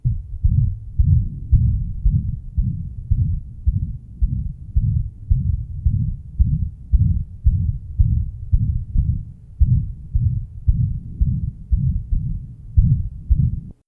HeartSounds Project Audio Player
Date 1972 Type Systolic Abnormality Atrial Septal Defect ASD and partial anomalous pulmonary venous drainage - very large L -> R shunt atrial level To listen, click on the link below.